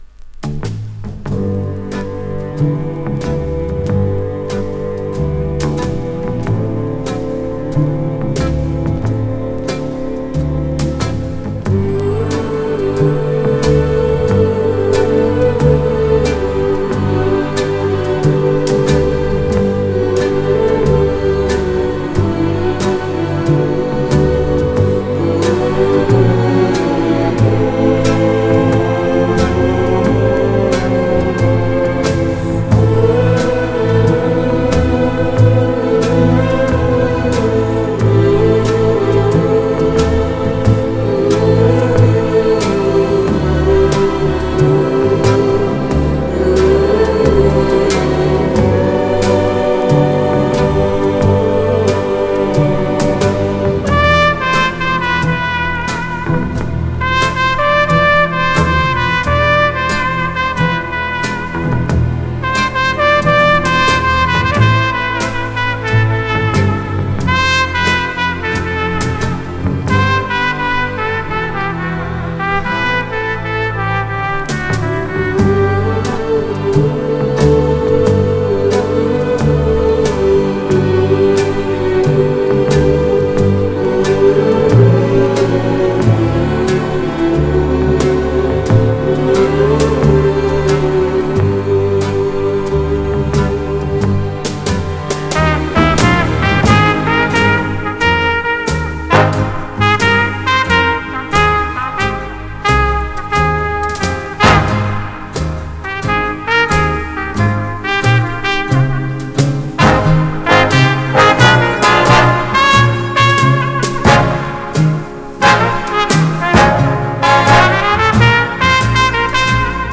Genre: Jazz, Pop
Style: Easy Listening, Swing